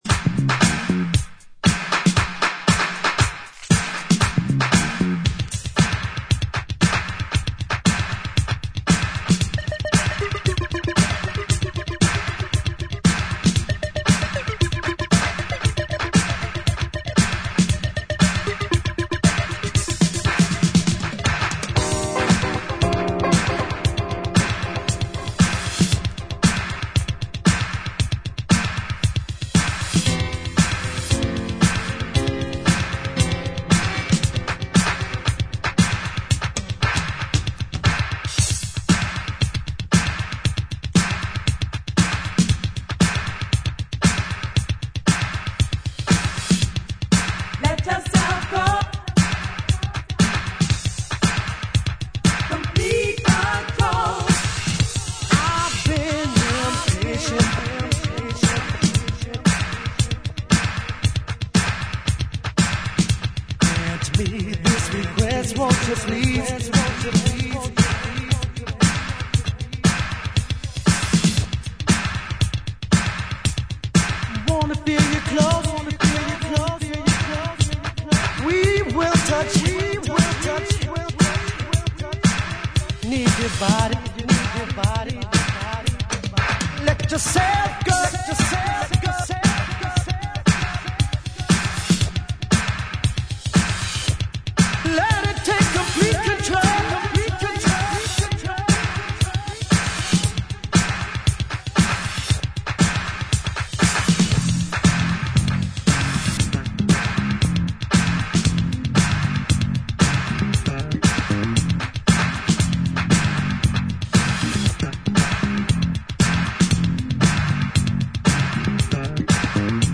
ジャンル(スタイル) DISCO / GARAGE / DANCE CLASSIC